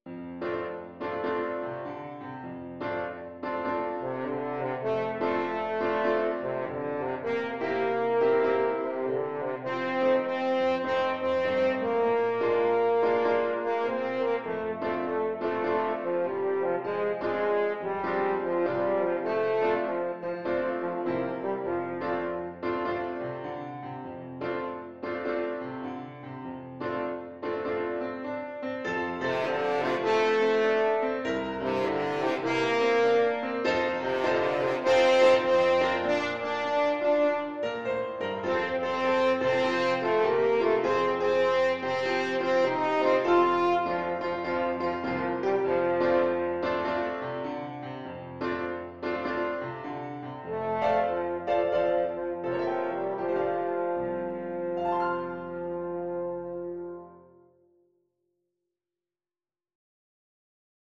4/4 (View more 4/4 Music)
Moderate swing